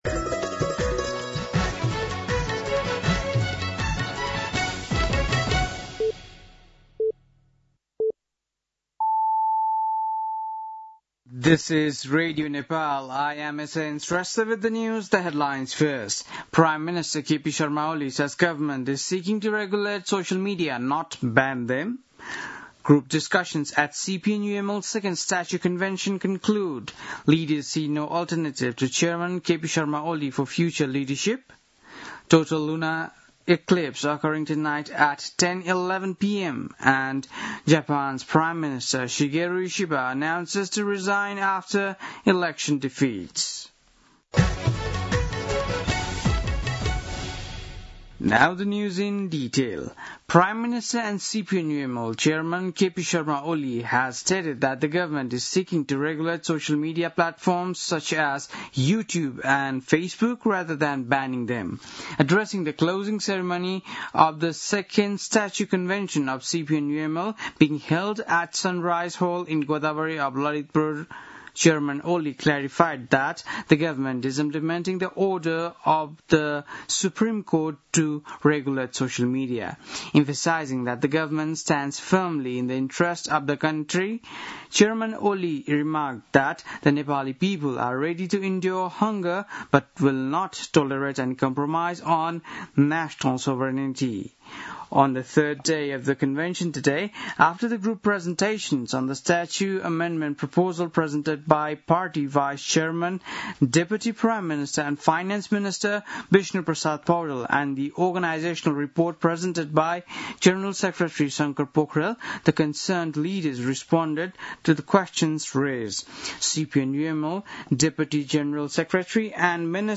बेलुकी ८ बजेको अङ्ग्रेजी समाचार : २२ भदौ , २०८२
8.-pm-english-news-.mp3